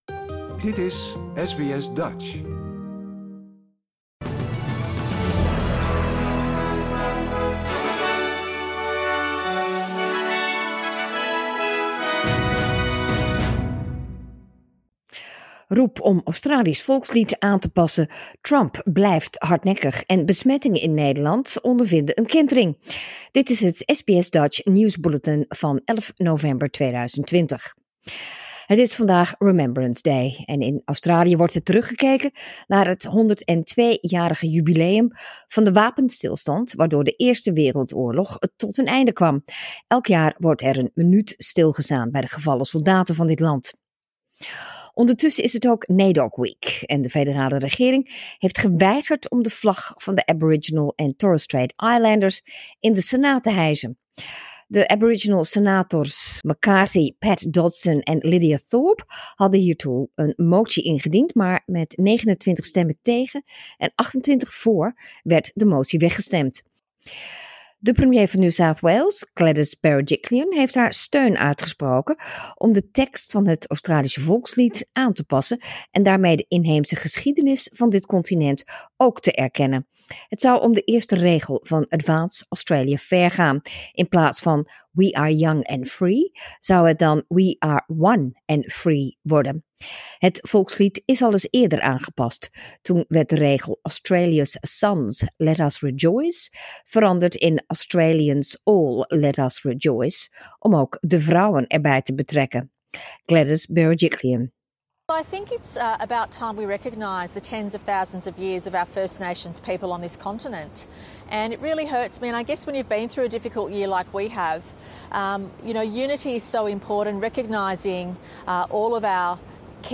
Nederlands/Australisch SBS Dutch nieuwsbulletin woensdag 11 november 2020